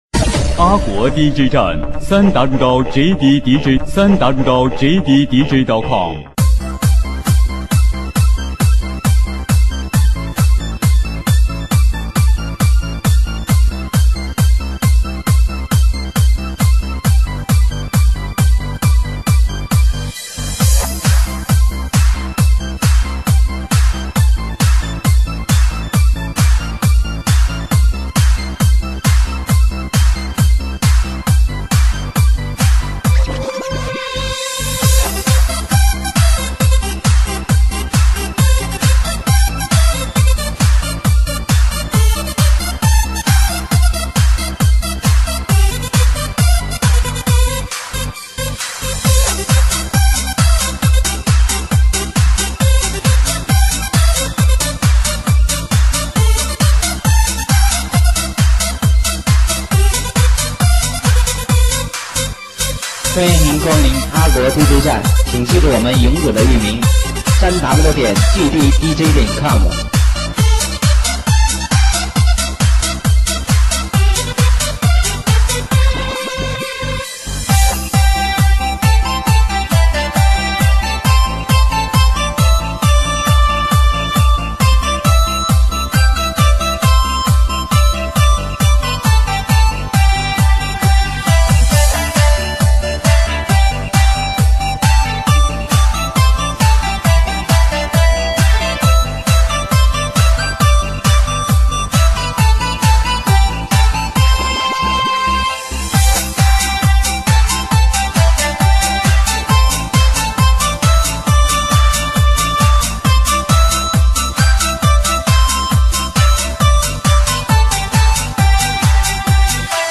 再求锁呐曲《十送红军》（纯音乐版）[已解决] 激动社区，陪你一起慢慢变老！